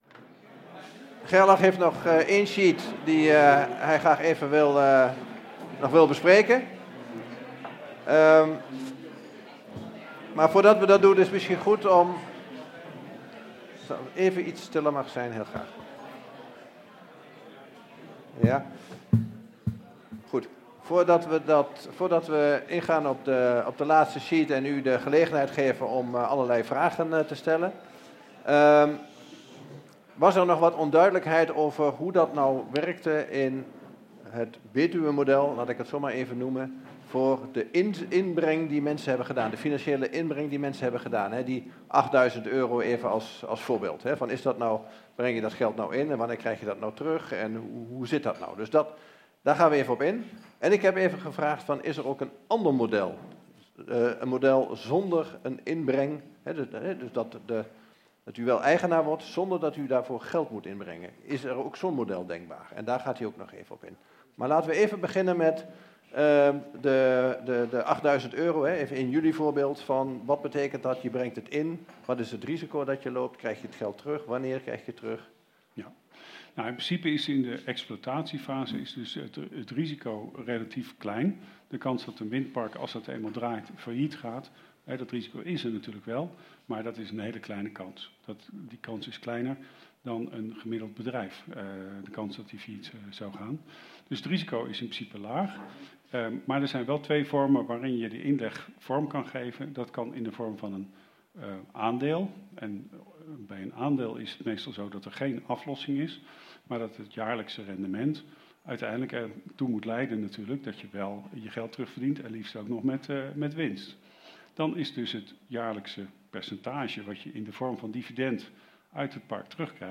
Er waren ongeveer 100 personen in de zaal. Deze themasessie is met toestemming van de zaal opgenomen.